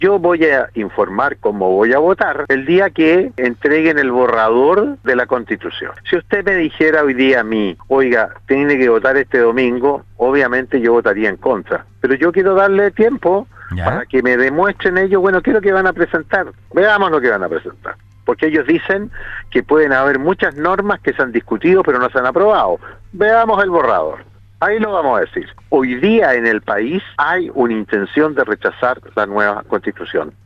En conversación con Radio Sago, el Senador por la región de Los Lagos, Iván Moreira (UDI), se refirió a su posición respecto al plebiscito de salida que se desarrollará en septiembre.